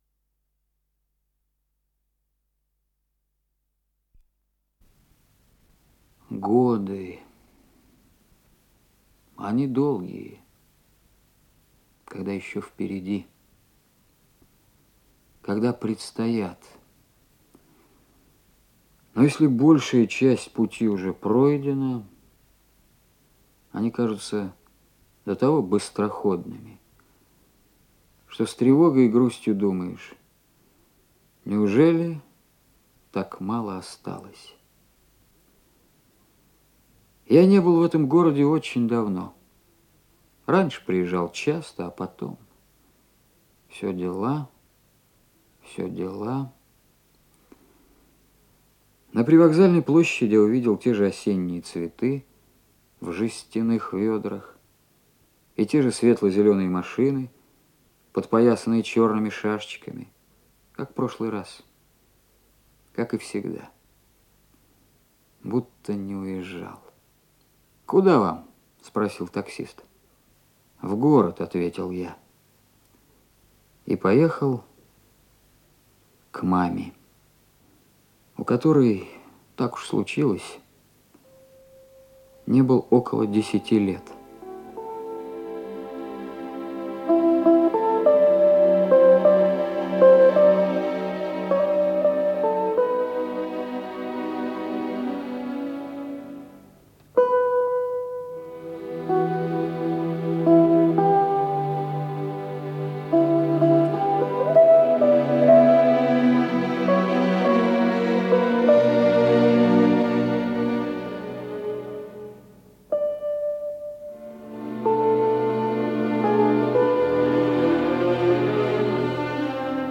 Инсценированные страницы повести